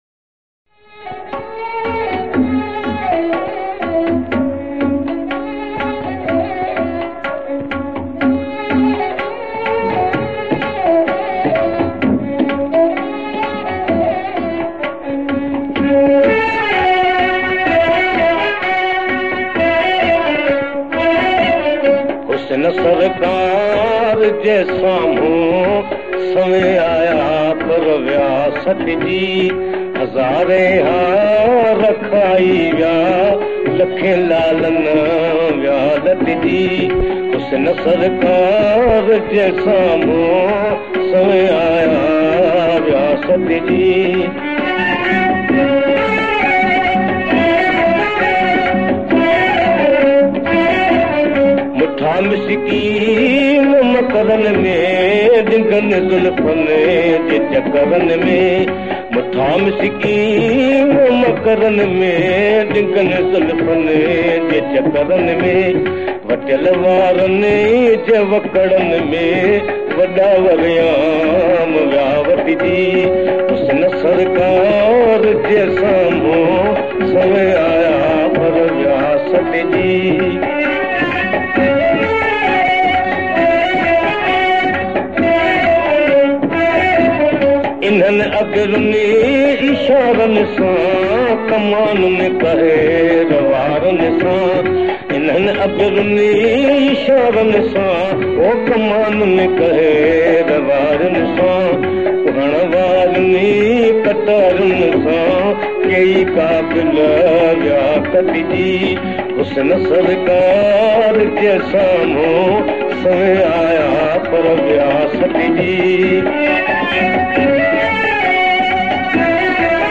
Classic Sindhi Songs